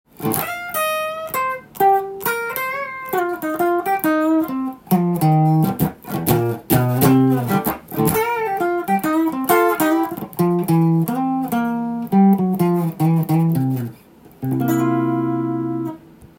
試しにこのギターを弾いてみました。
リアのピックアップマイクで弾いたところ、驚きのサウンドが出ました。
ハイ（高音）が全然でません！
高音域が出ないビンテージの音に衝撃を受けました。
おじいちゃんが頑張って喋ってる感じです。